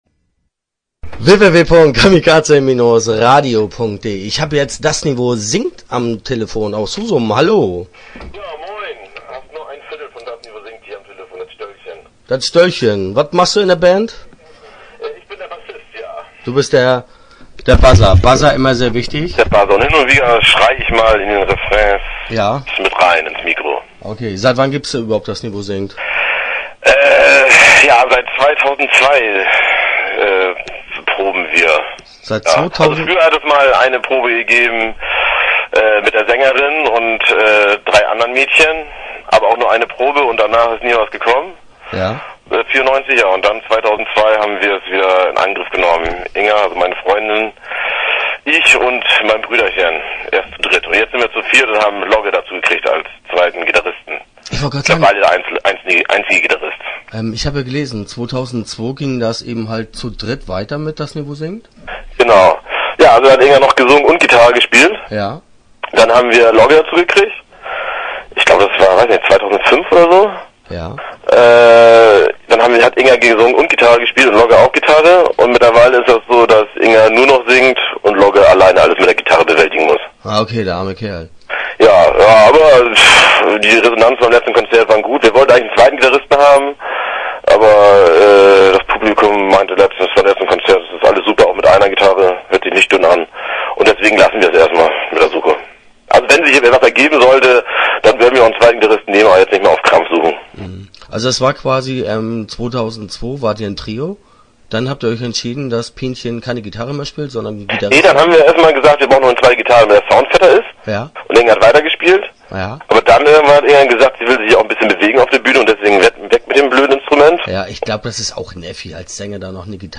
Start » Interviews » Das Niveau singt